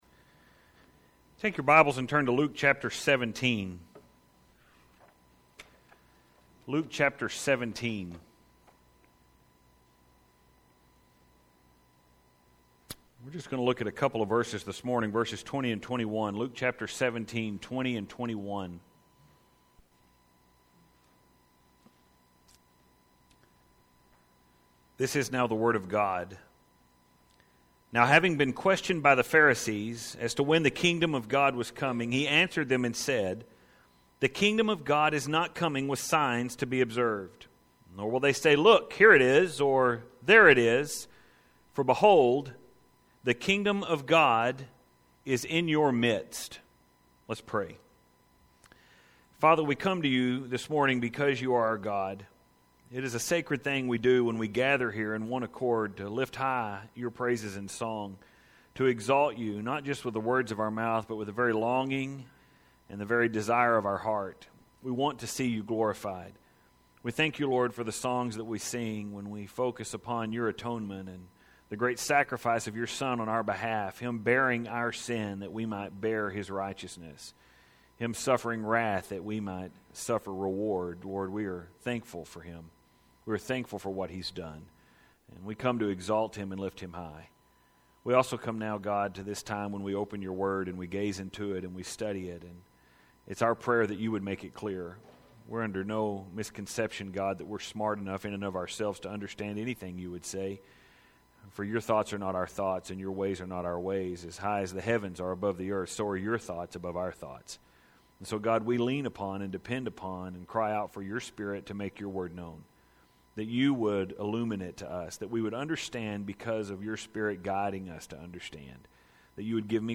You see here the setting of the sermon.